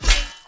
melee-hit-10.ogg